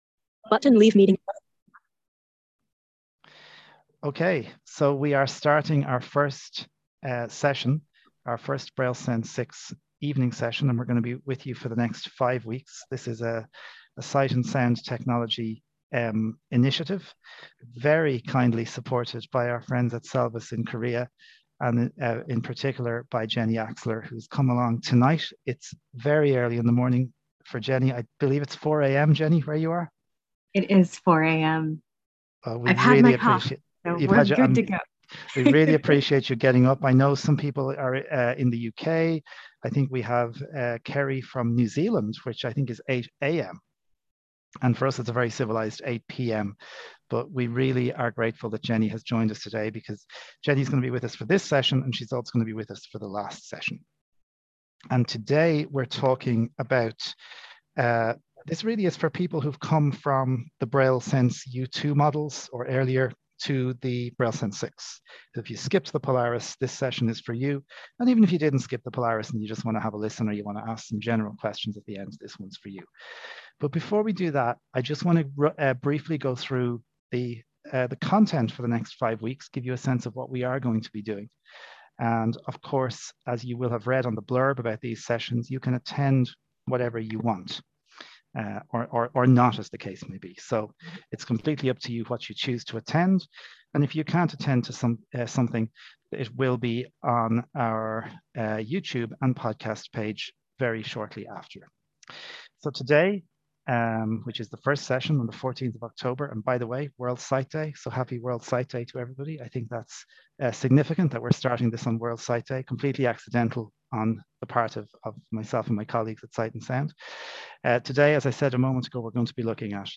Welcome to the first in a series of five training sessions devoted to the BrailleSense 6.